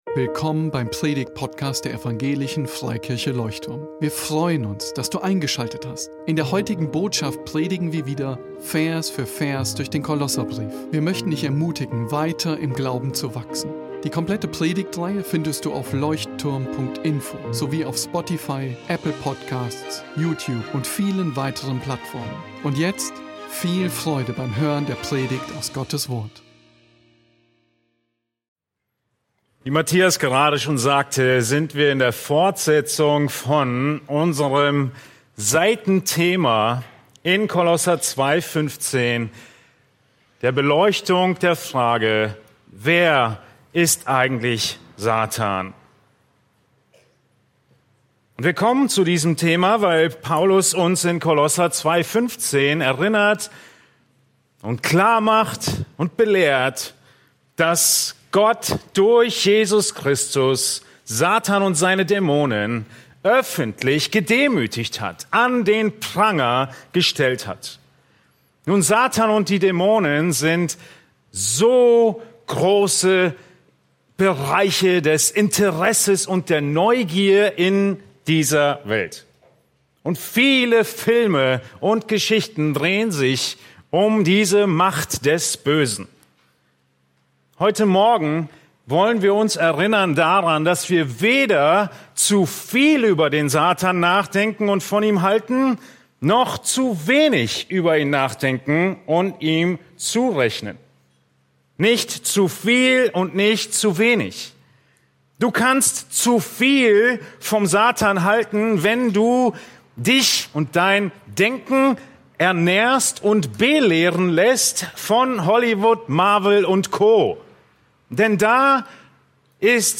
In dieser Predigt aus Kolosser 2,15 wird deutlich, wie Jesus Christus am Kreuz den Satan besiegt und entmachtet hat.